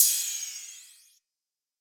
TS - PERC (2).wav